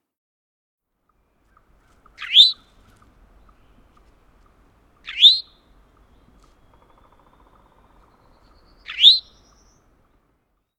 Eastern Towhee
How they sound: The classic Eastern Towhee song, given by males, is a loud drink-your-tea! , lasting about 1 second. The most common call is a two-parted tow-hee !